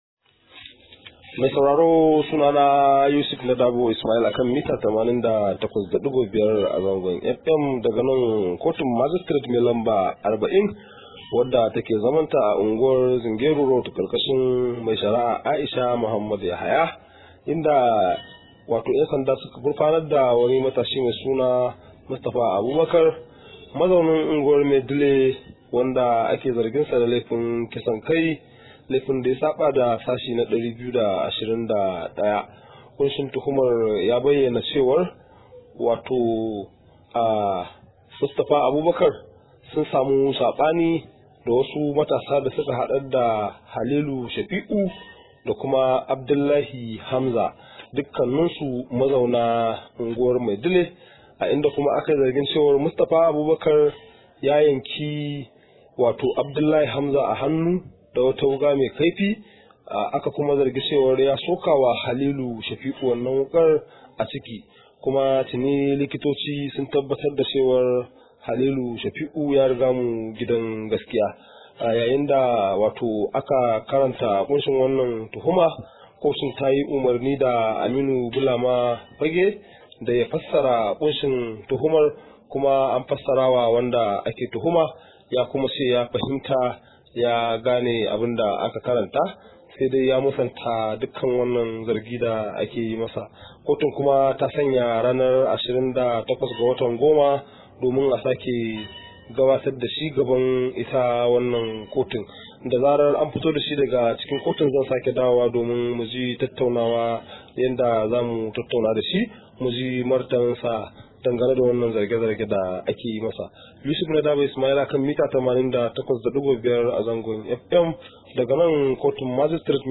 Rahoto: A na tuhumar matashi dan Medile da kisan kai